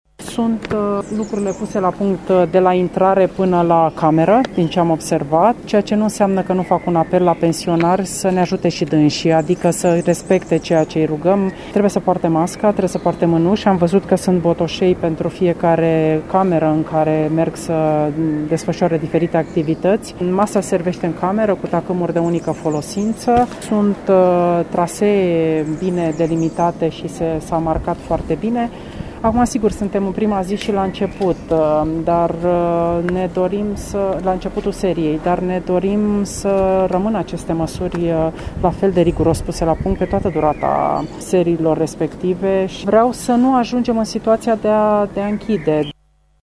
Pensionarii sunt rugați să respecte regulile din centrele de tratament pentru a nu se ajunge în situația ca acestea să fie închise. Ministrul muncii spune că au fost luate toate măsurile pentru ca tratamentele să fie făcute în siguranță.